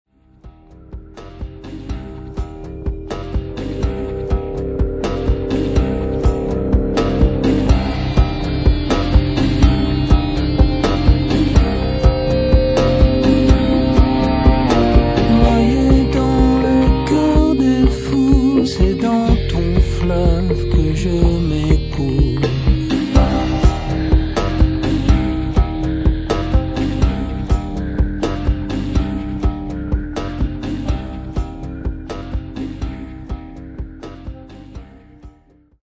clavier
chœurs